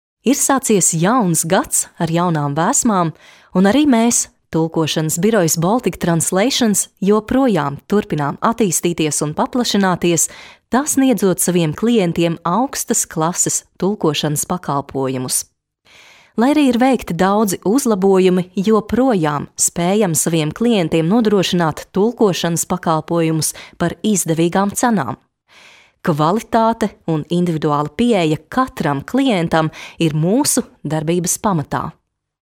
Kadın Ses